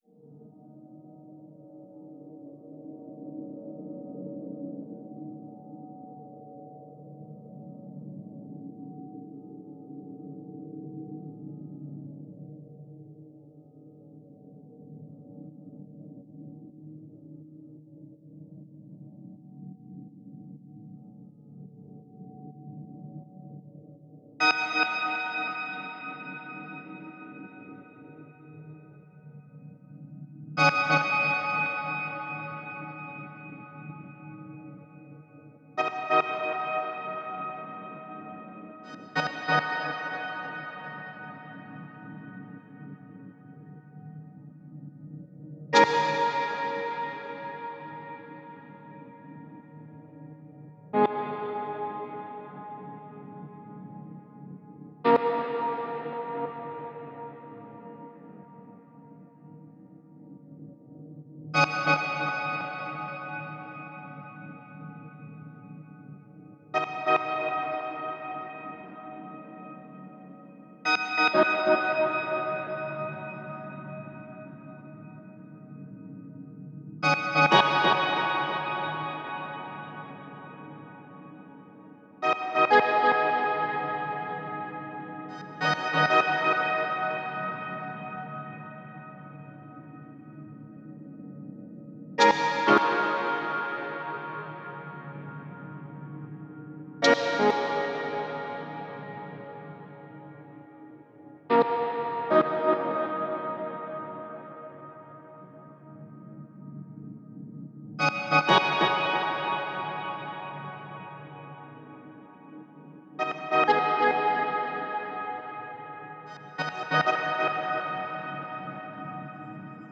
Hydrophobia_synths.wav